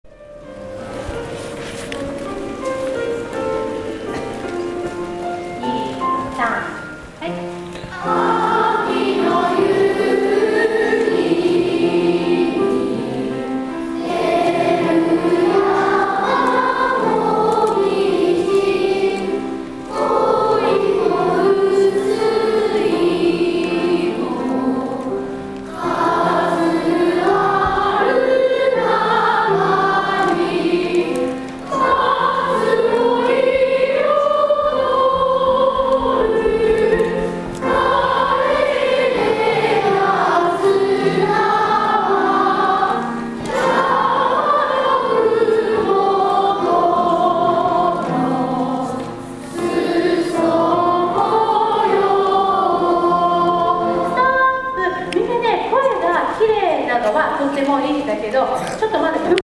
創立４９周年記念芸術鑑賞会